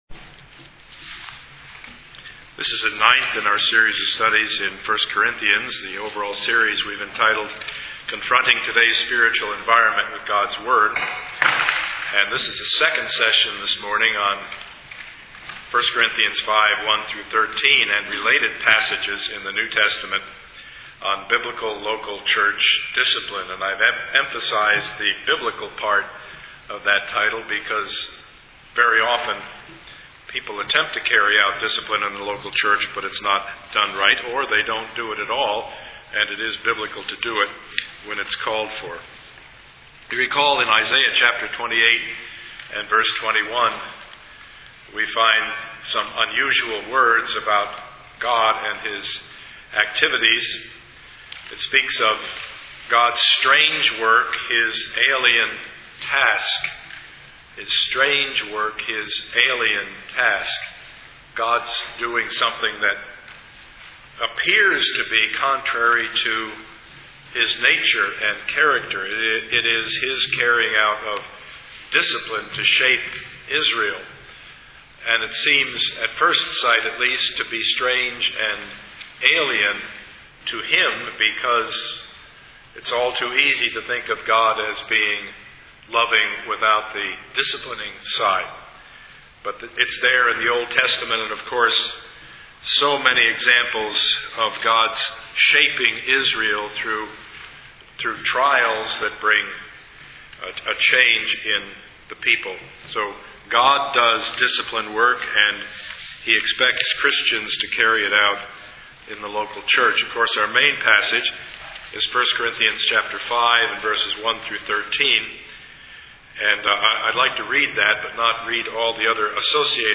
Service Type: Sunday morning
Part 9 of the Sermon Series